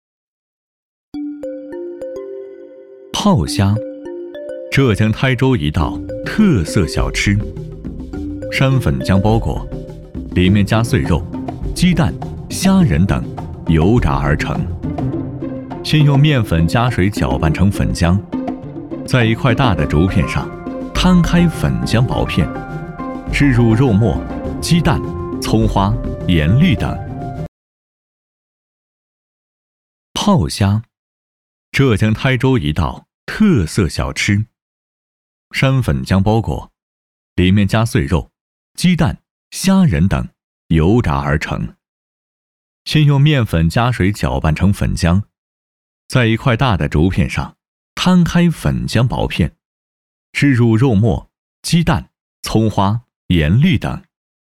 专业男声舌尖体配音！